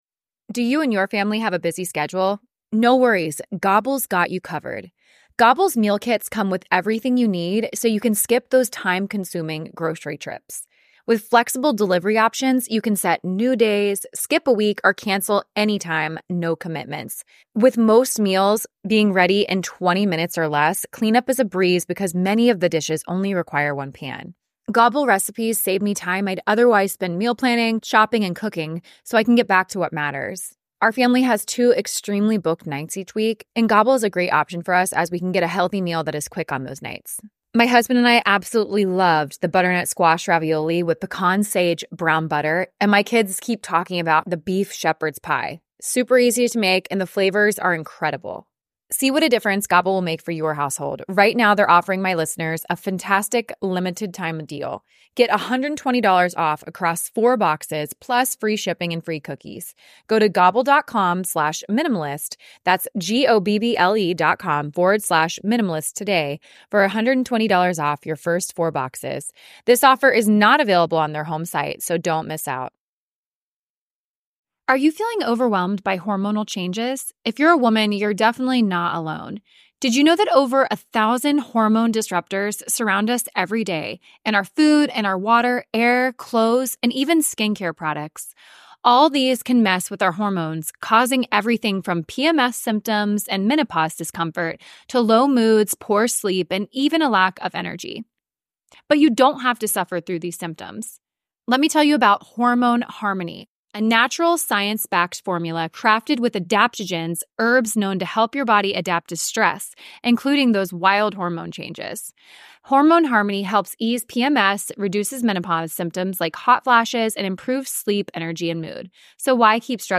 Join me today for a bonus conversation with the voices behind the popular TikTok and Instagram account, Excuse My Grandma!